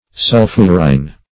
Sulphurine \Sul"phur*ine\, a.